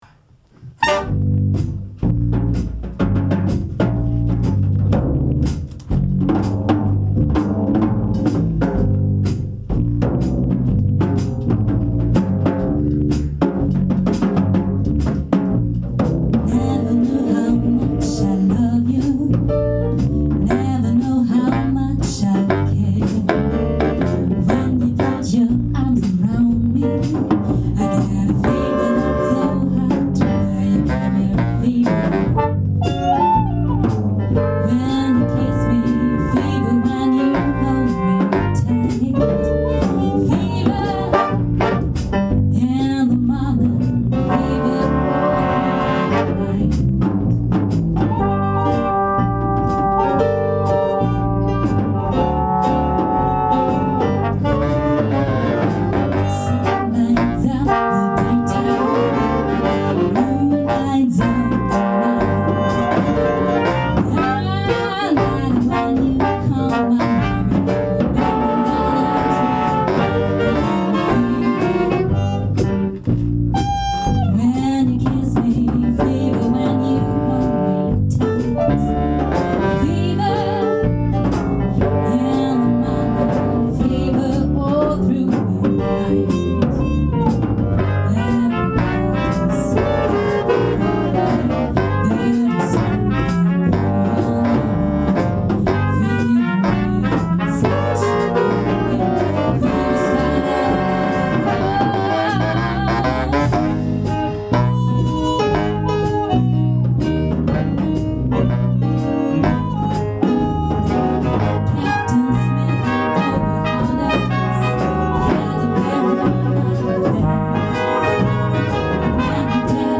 Probe
· Genre (Stil): Big Band
· Kanal-Modus: stereo · Kommentar